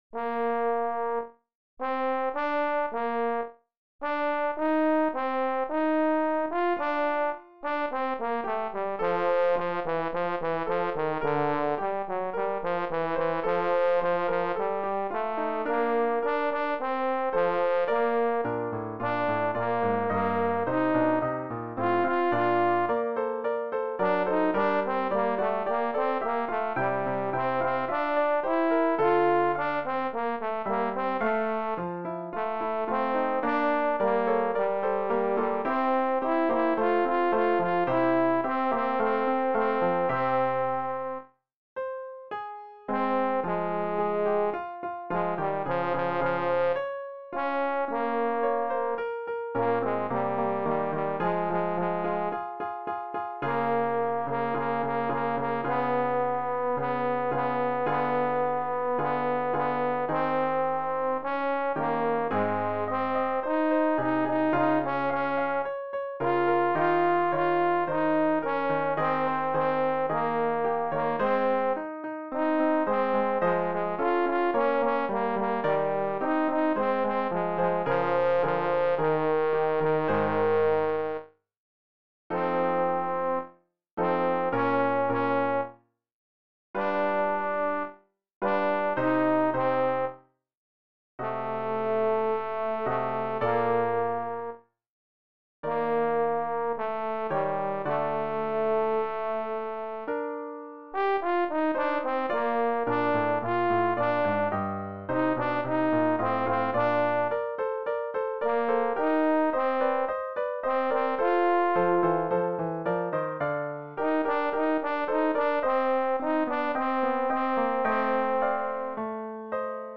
tenor-haydn-warnung.mp3